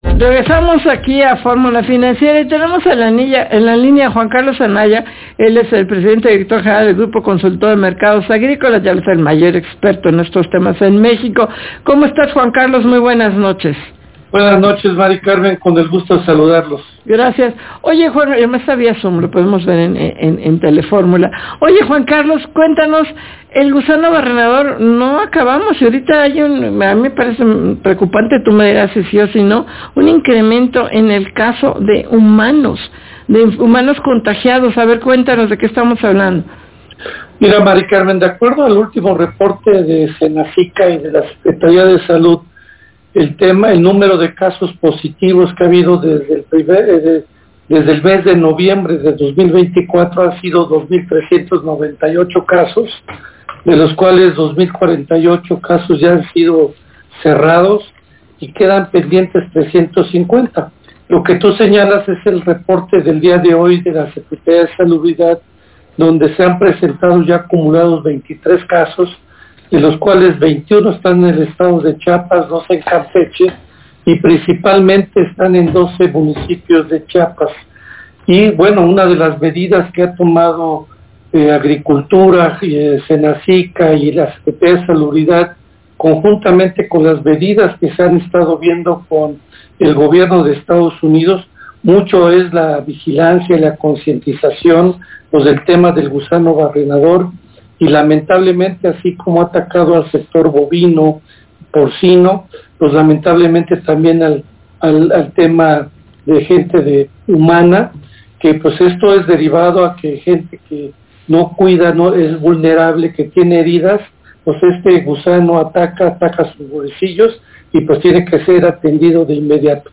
Fórmula Financiera: Entrevista